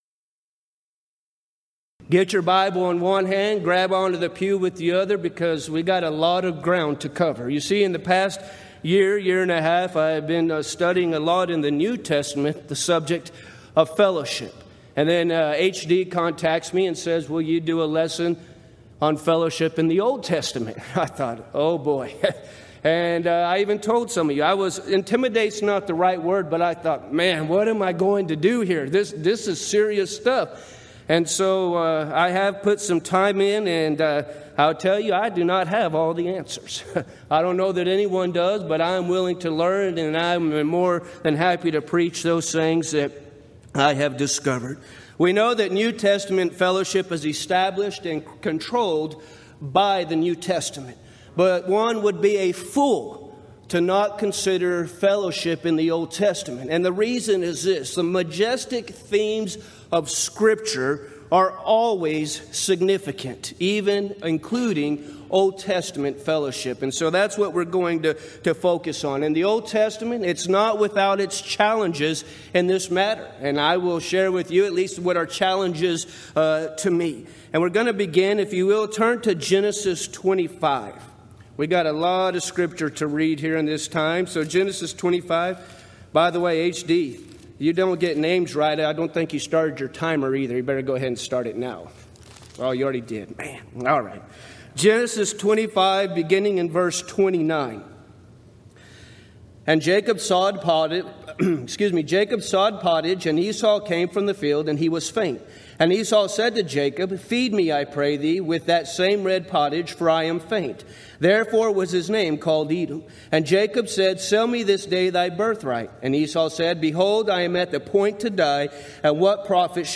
Event: 24th Annual Gulf Coast Lectures Theme/Title: Christian Fellowship
lecture